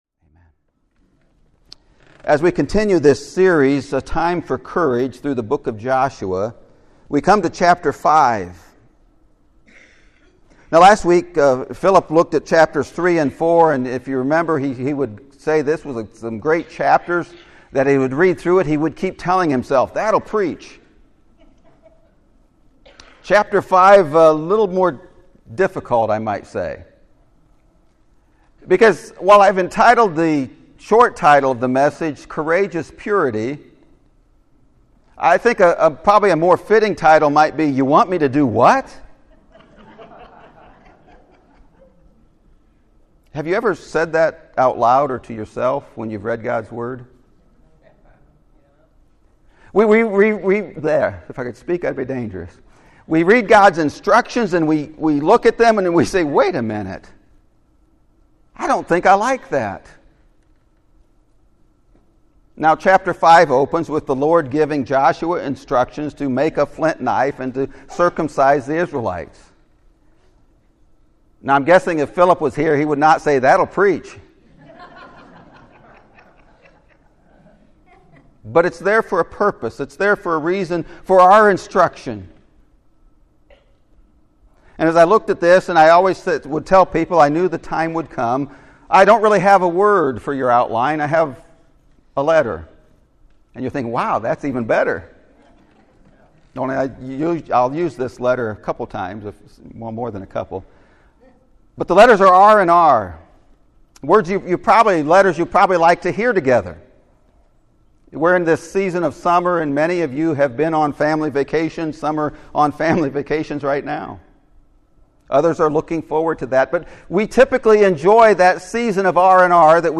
Courageous Purity! (Sermon Audio)